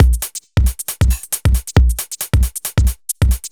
Index of /musicradar/uk-garage-samples/136bpm Lines n Loops/Beats